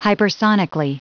Prononciation du mot hypersonically en anglais (fichier audio)
Prononciation du mot : hypersonically